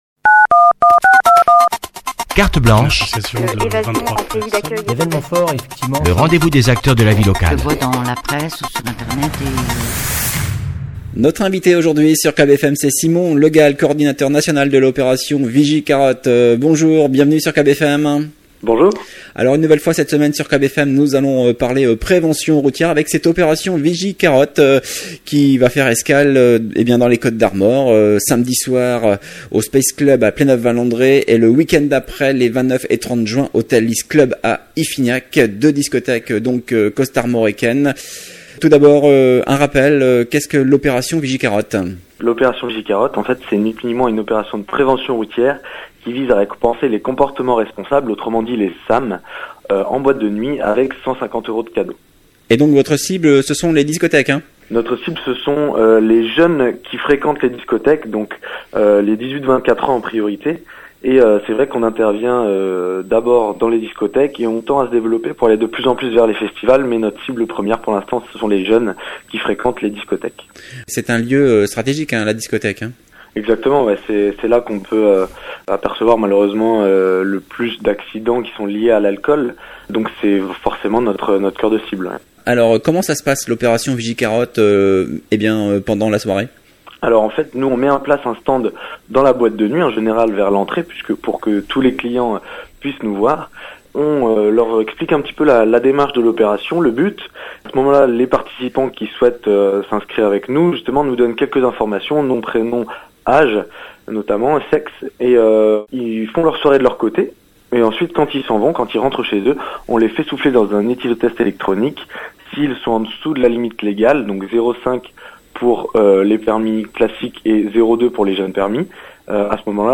Cette entrée a été publiée dans Interviews.